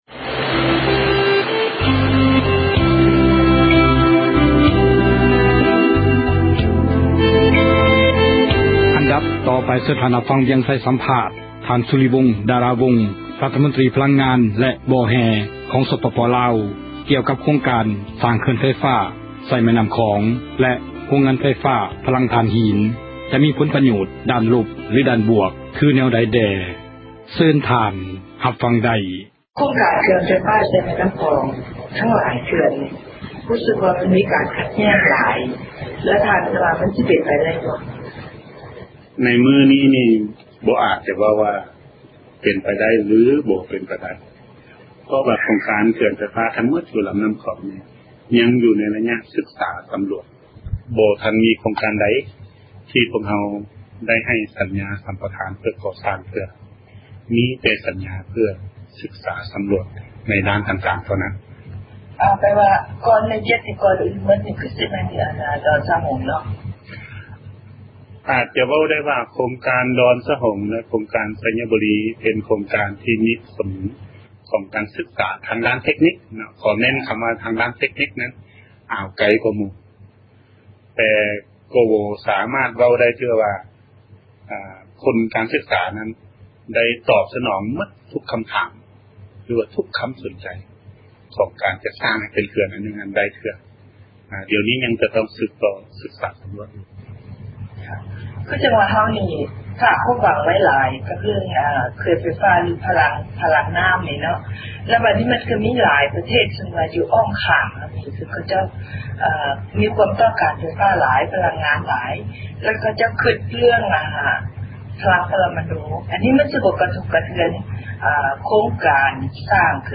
ສັມພາດຣັຖມົນຕຣີ ພລັງງານແລະບໍ່ແຮ່ ຂອງລາວ